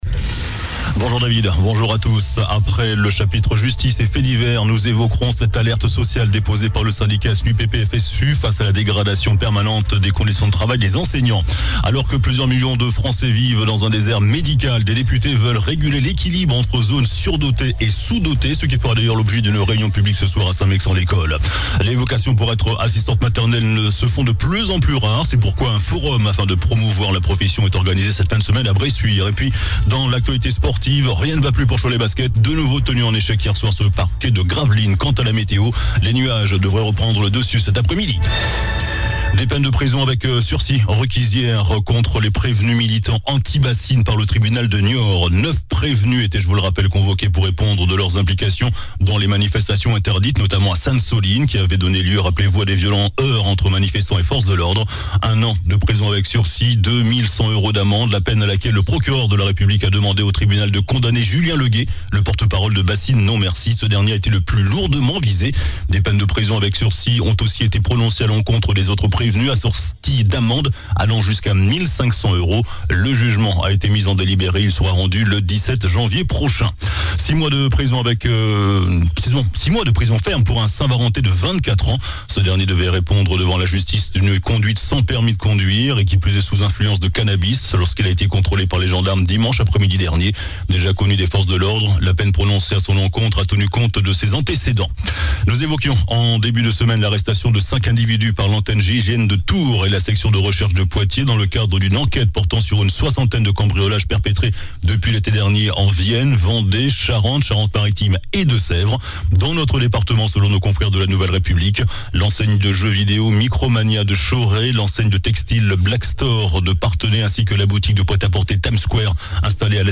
JOURNAL DU MERCREDI 29 NOVEMBRE ( MIDI )